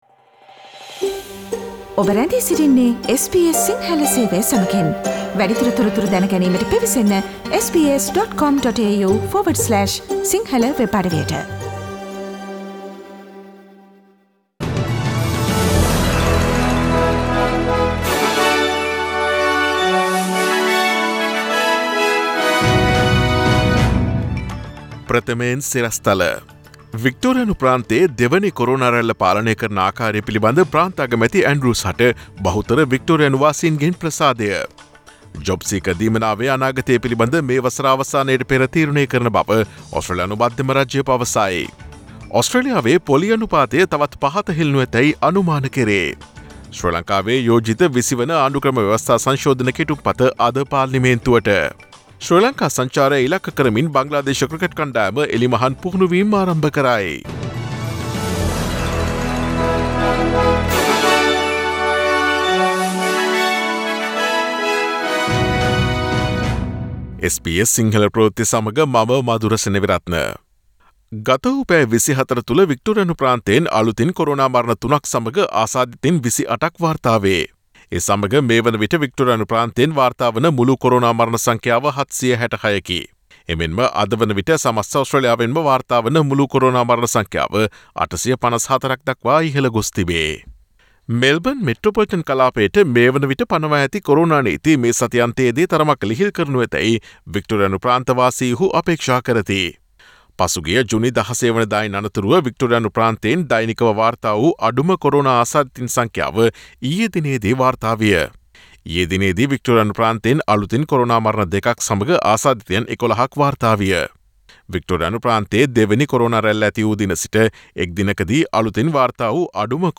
Daily News bulletin of SBS Sinhala Service: Tuesday 22 September 2020